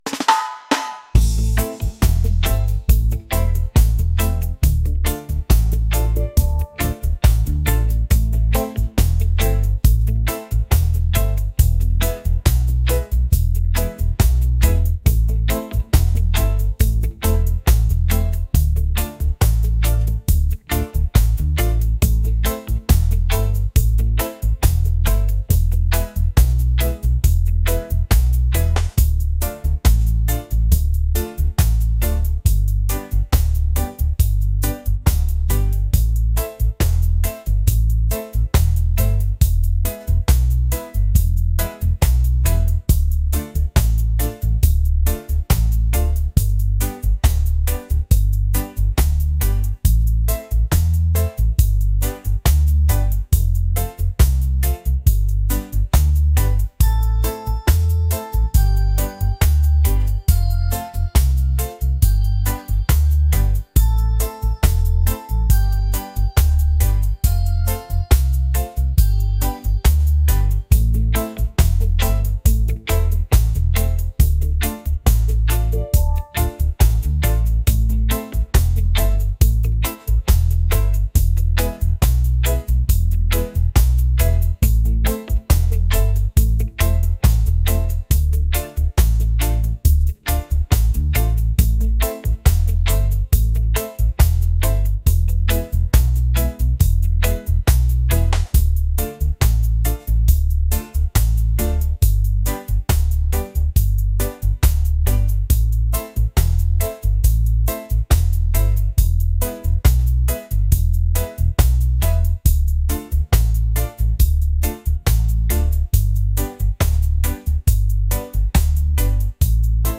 romantic | laid-back | reggae